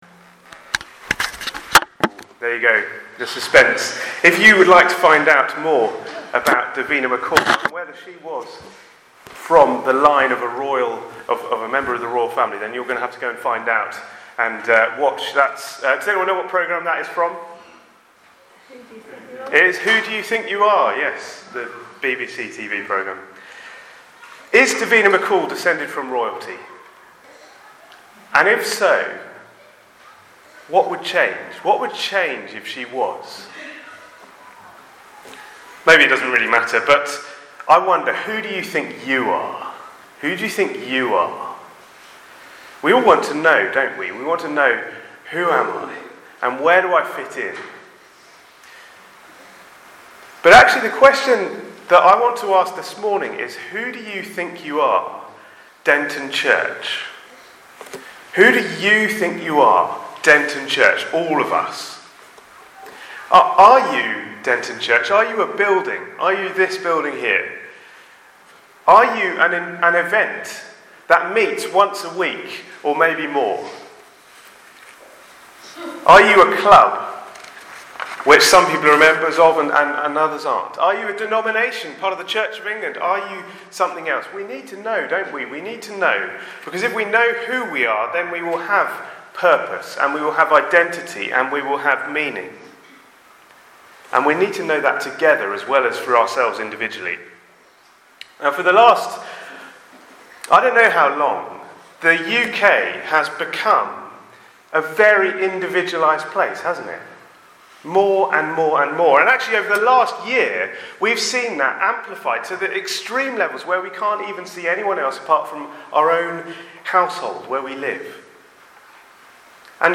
Apologies, as this recording has missed about the first 30 seconds.